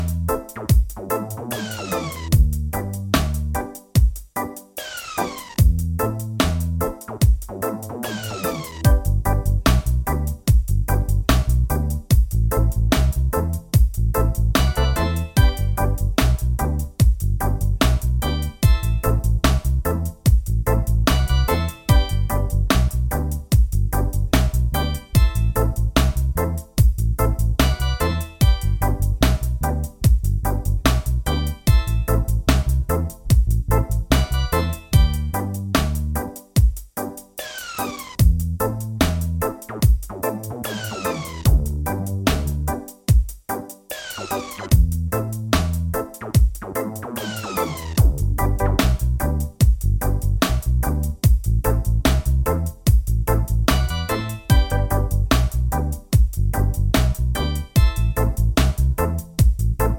Minus Main Guitar For Guitarists 4:30 Buy £1.50